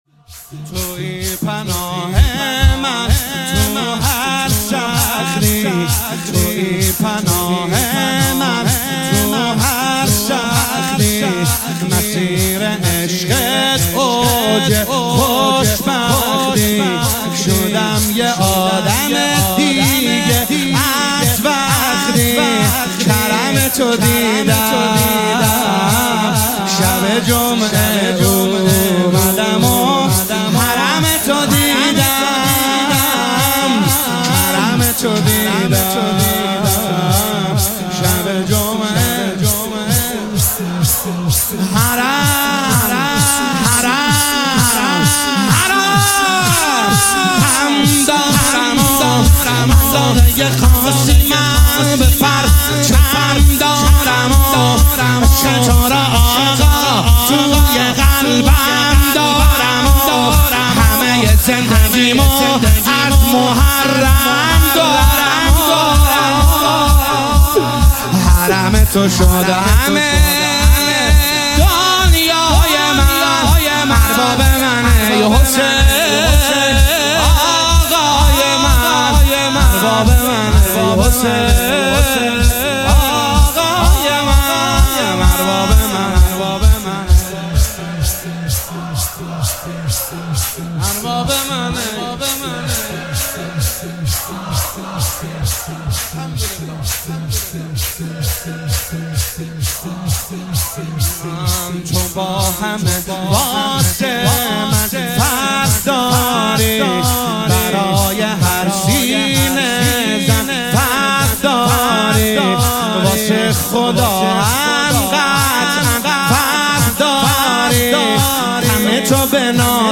متن شور امام حسین